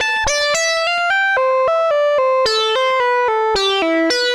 Index of /musicradar/80s-heat-samples/110bpm